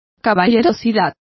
Complete with pronunciation of the translation of chivalry.